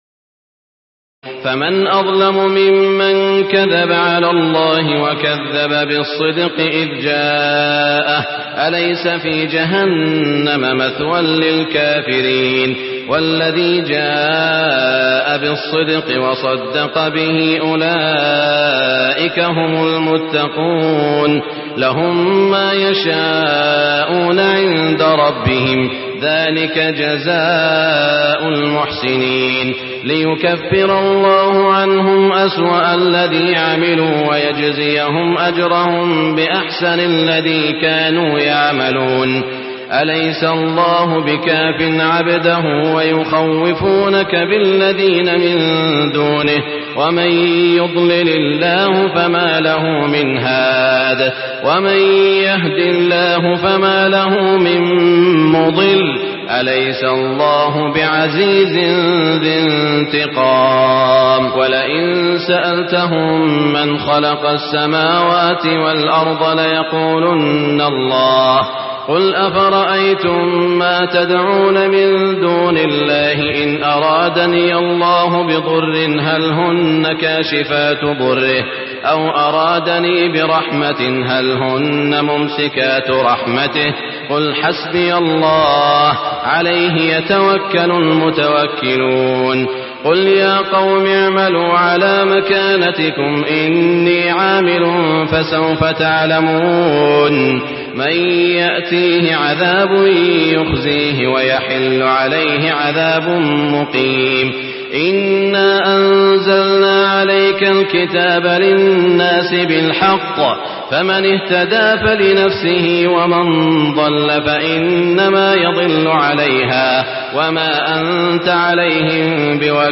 تراويح ليلة 23 رمضان 1423هـ من سور الزمر(32-75) وغافر (1-40) Taraweeh 23 st night Ramadan 1423H from Surah Az-Zumar and Ghaafir > تراويح الحرم المكي عام 1423 🕋 > التراويح - تلاوات الحرمين